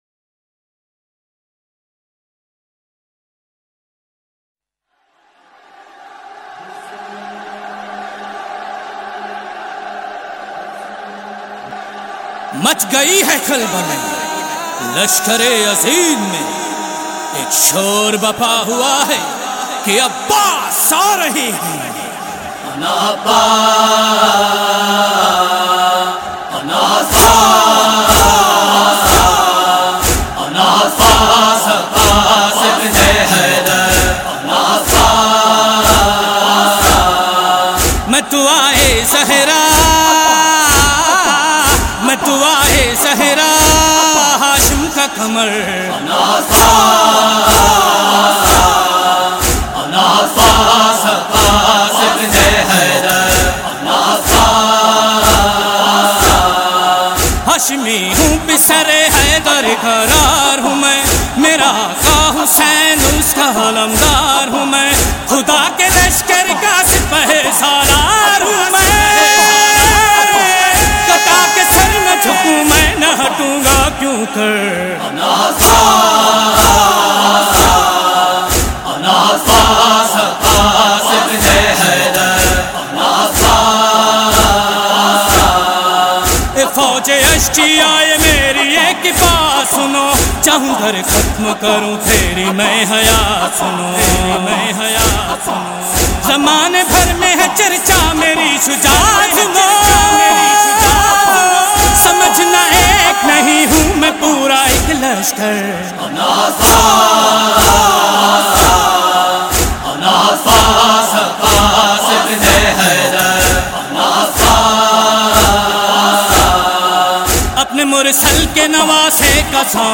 Sindhi Noha